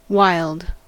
wild: Wikimedia Commons US English Pronunciations
En-us-wild.WAV